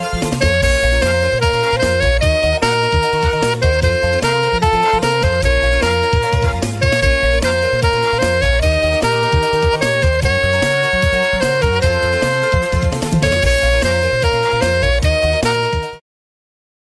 230 Простых мелодий для саксофониста